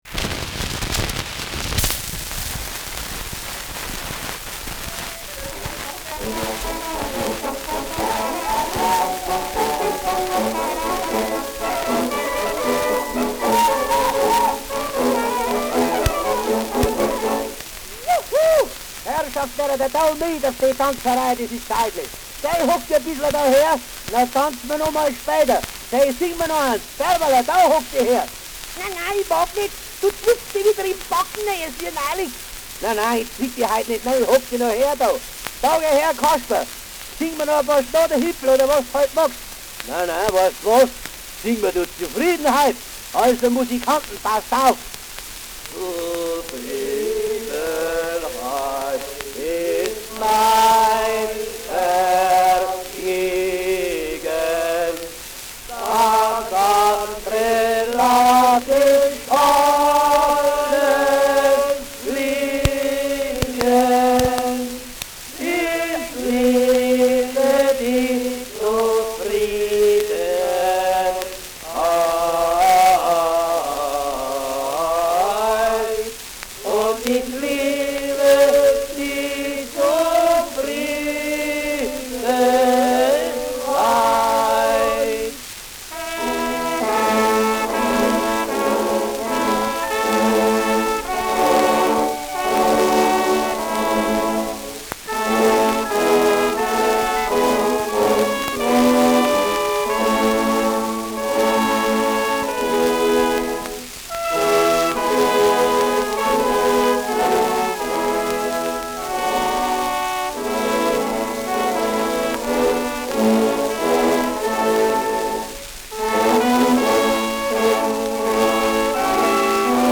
Schellackplatte
Stark abgespielt : Durchgehend leichtes Knacken
[Ansbach] (Aufnahmeort)
Humoristischer Vortrag* FVS-00003